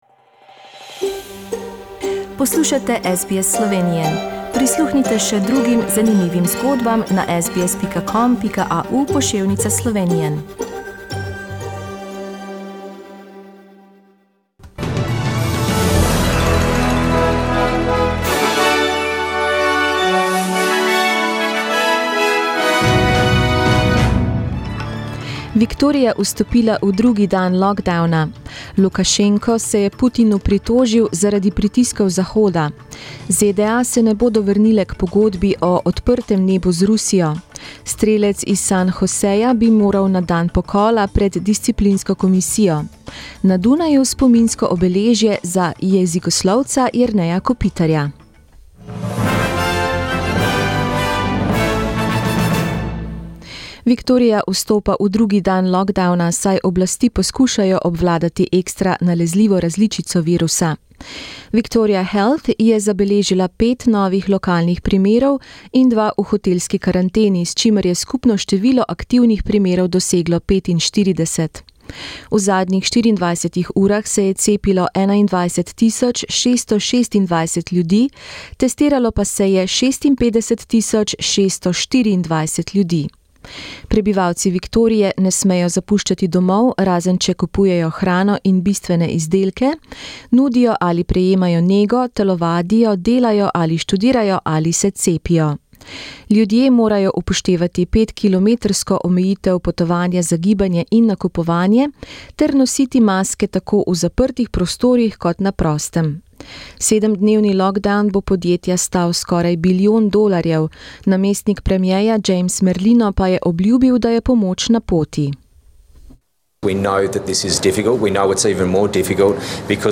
Today's news in Slovenian